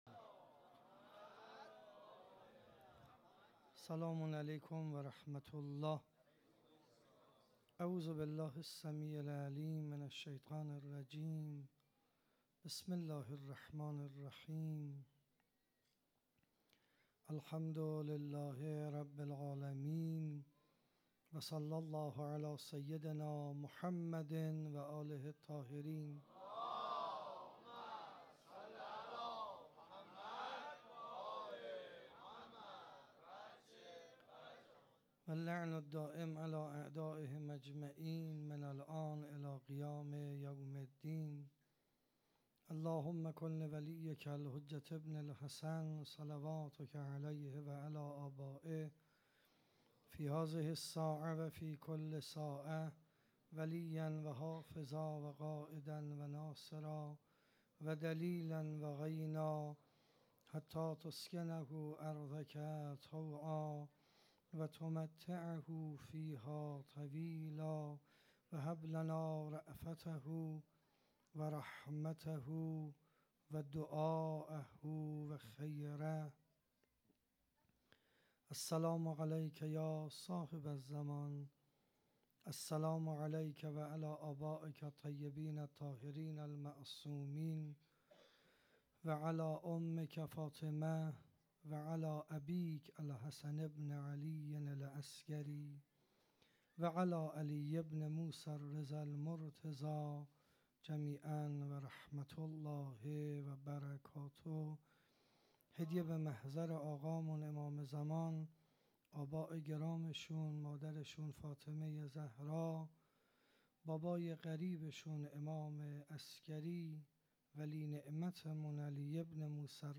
مدیحه‌سرایی و روضه‌خوانی
در حسینیۀ شهداء واقع در میدان پونک برگزار شد.
صوت کامل سخنرانی